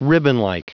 Prononciation du mot ribbonlike en anglais (fichier audio)
Prononciation du mot : ribbonlike